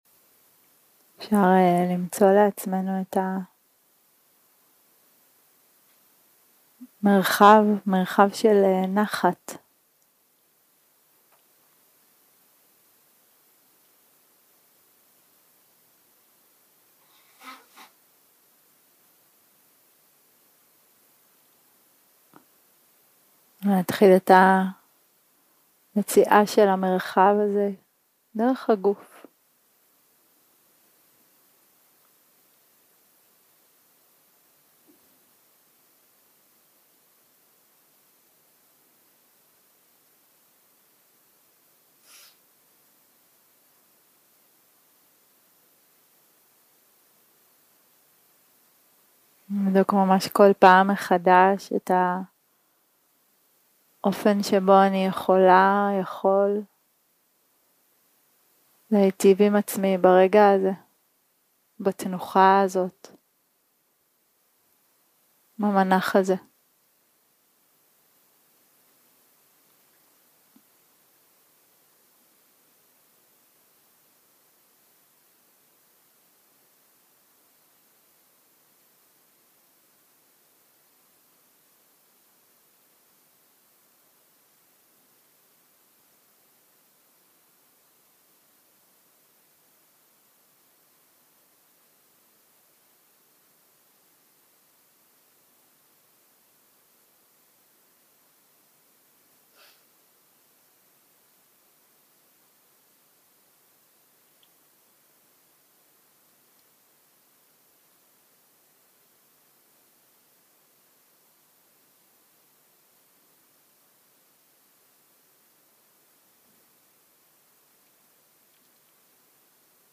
שיחת הנחיות למדיטציה